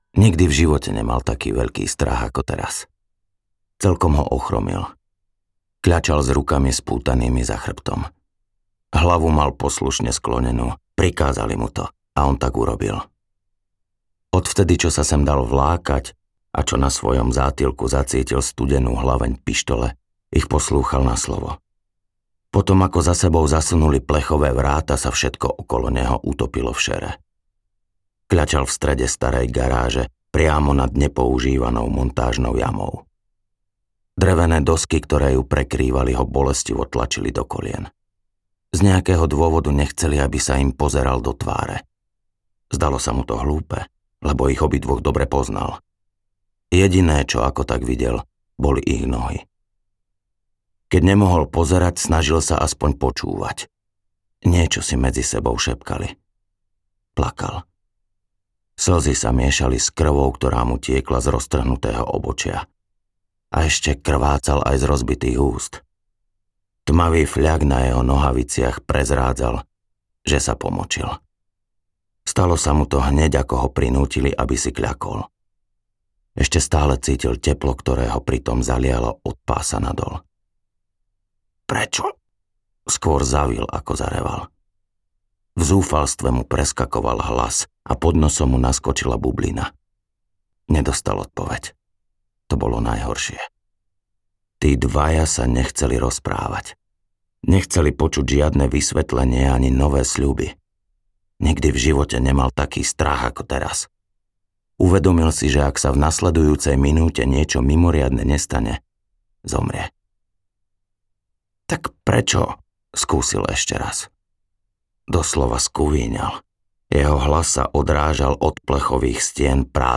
Vražda s pridanou hodnotou audiokniha
Ukázka z knihy